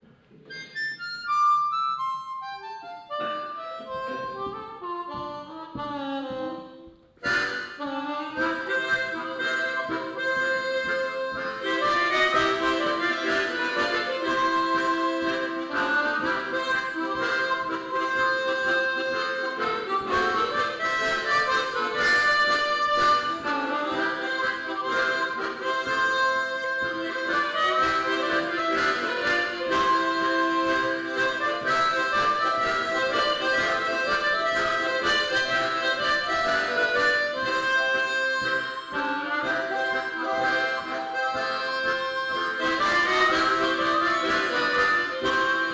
Tremolo